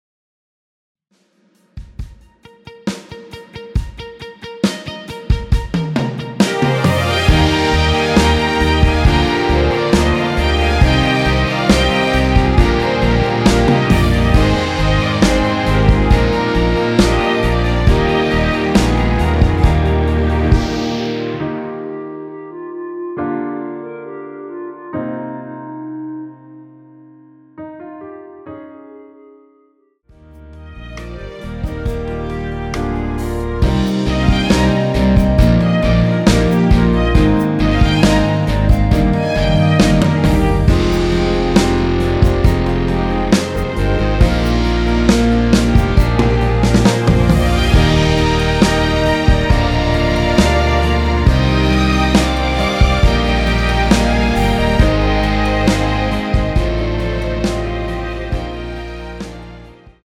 원키에서(-1)내린 멜로디 포함된 MR입니다.(미리듣기 확인)
Eb
앞부분30초, 뒷부분30초씩 편집해서 올려 드리고 있습니다.
중간에 음이 끈어지고 다시 나오는 이유는